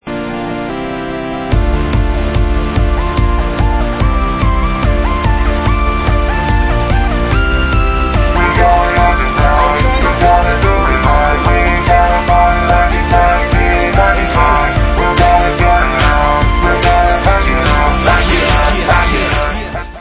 POST-HARDCORE WITH ELECTRONIC APPROACH